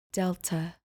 Pronounced: DEL-ta